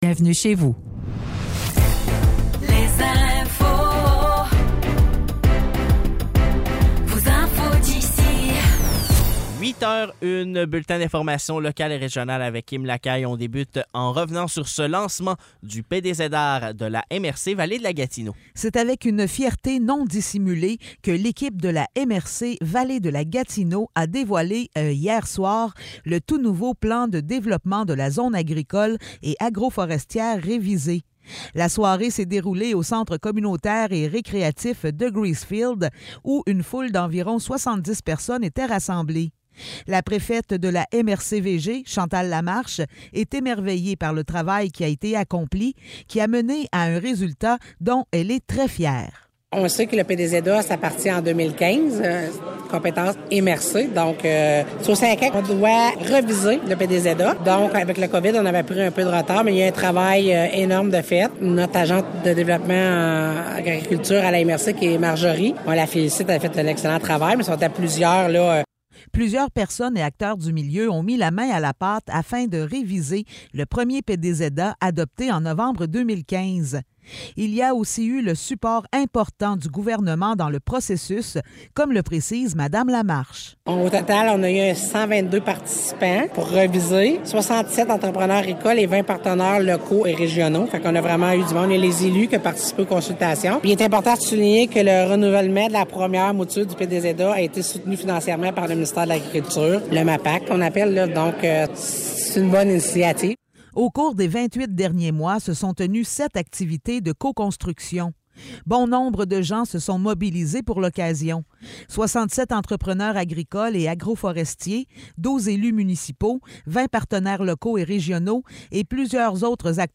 Nouvelles locales - 23 novembre 2023 - 8 h